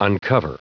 Prononciation du mot uncover en anglais (fichier audio)
Prononciation du mot : uncover